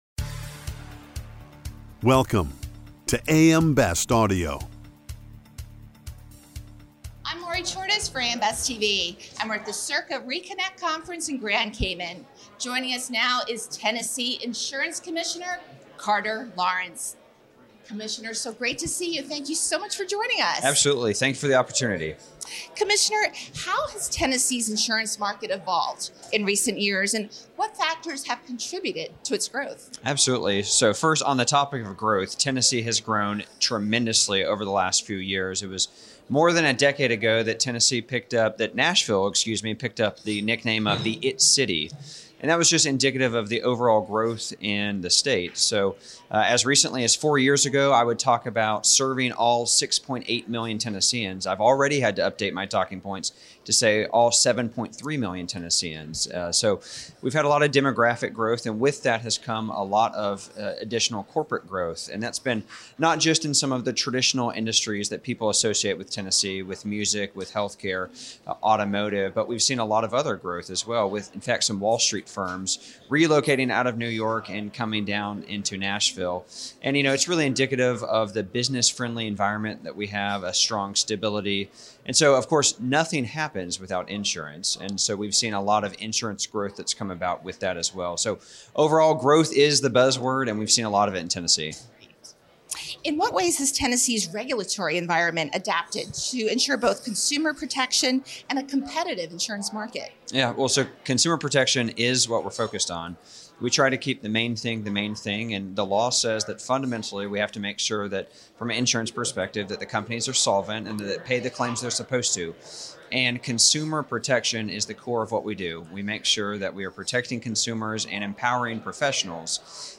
Carter Lawrence, commissioner of the Tennessee Department of Commerce and Insurance, said choices and competition in a business-friendly environment will help the insurance market meet the needs of the state’s growing population. Lawrence spoke with AM Best TV at the CIRCA ReConnect Conference in Grand Cayman.…